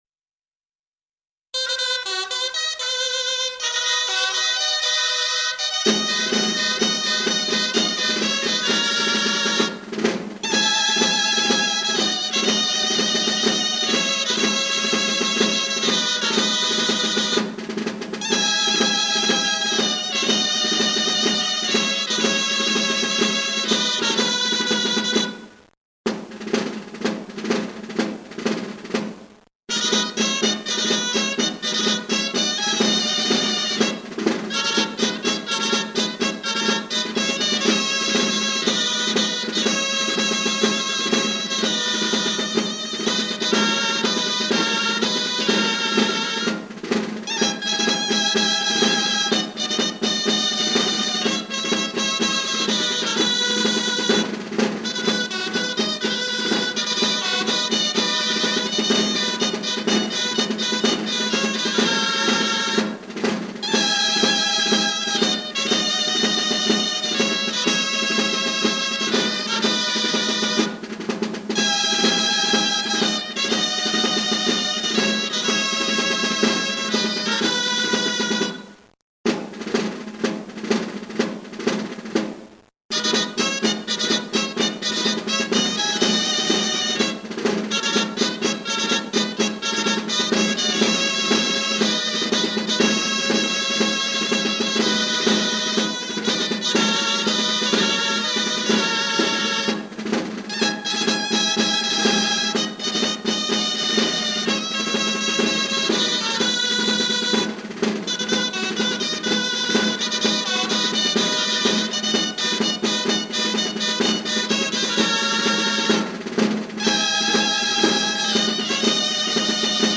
VENT-FUSTA
Gralla i tabal:
6. Gralles i tabal
6.-Gralles-i-tabal1.mp3